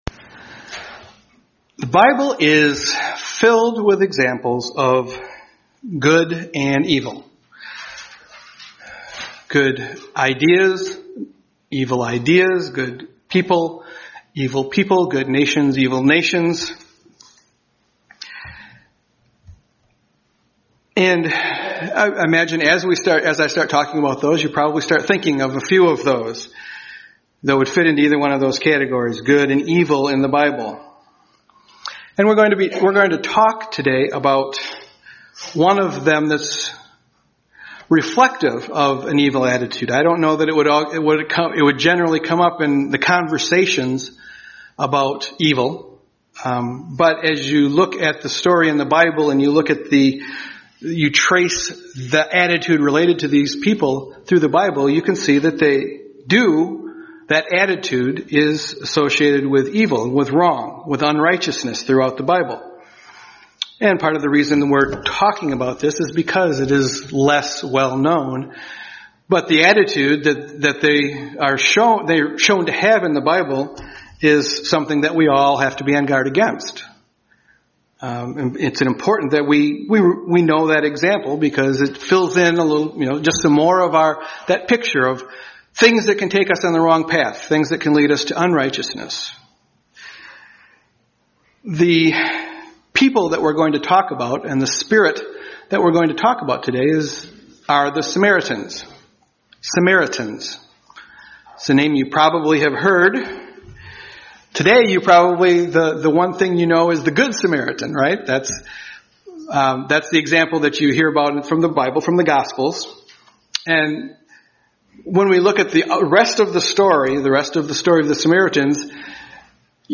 Given in Grand Rapids, MI